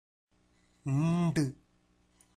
Nasals (Mellinam – Weak Sounds)
 = n-d